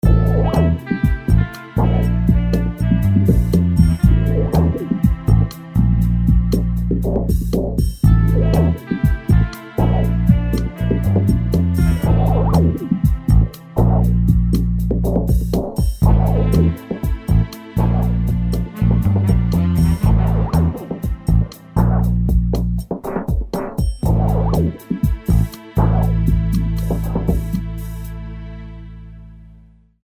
instrumental music cues can also be used to